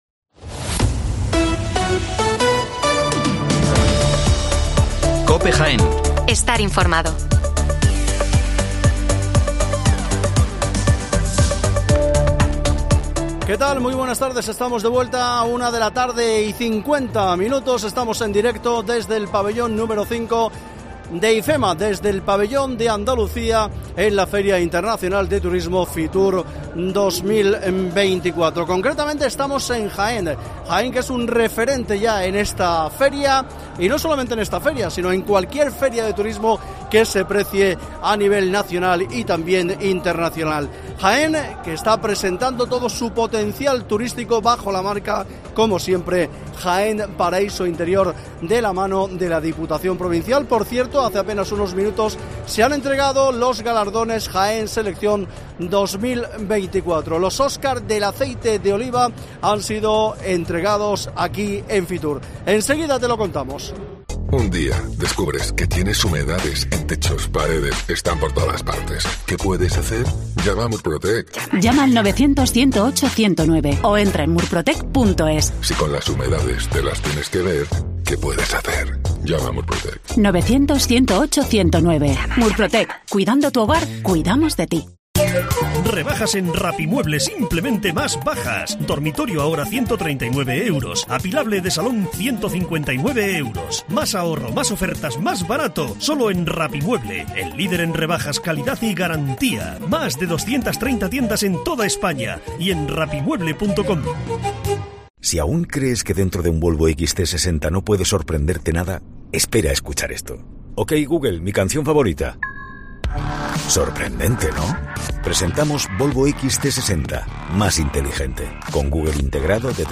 AUDIO: Programa esoecial desde FITUR 2024 con el apoyo de la Diputación Provincial de Jaén